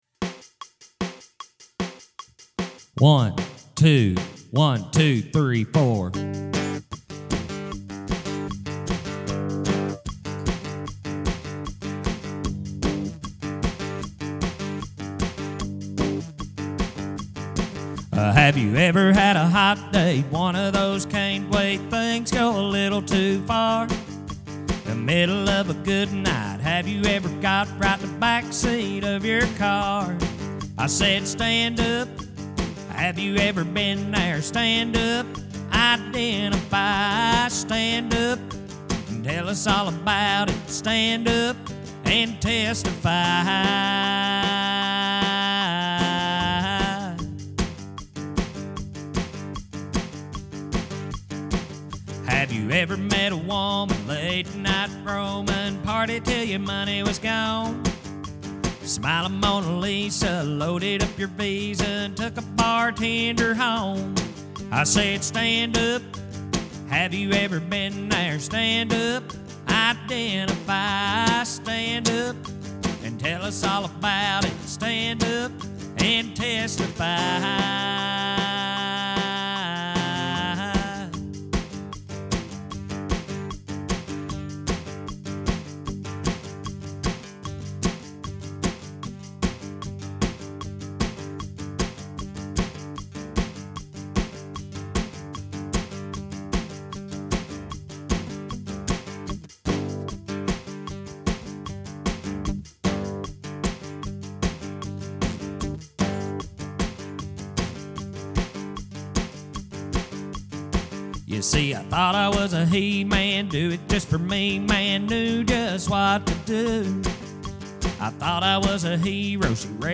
KEY G - TEMPO 152